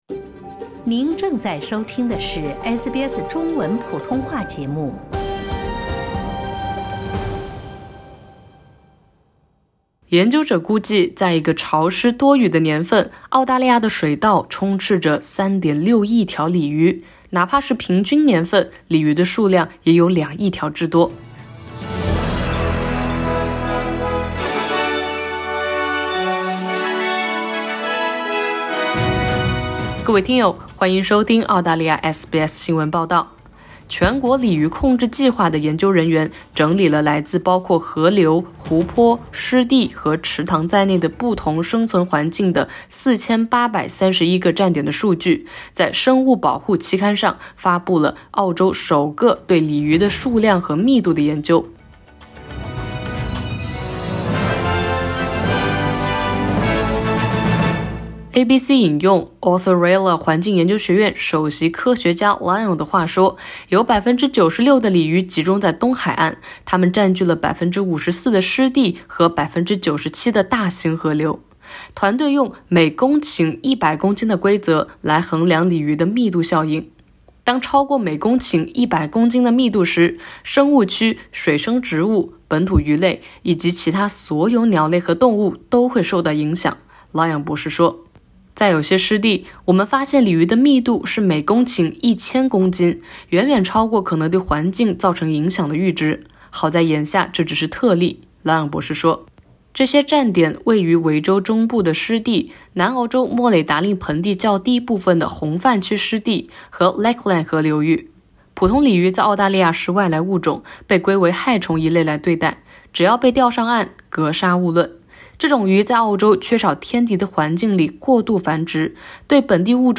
普通鲤鱼在澳大利亚是外来物种，被归为害虫一类来对待。(点击上图收听报道)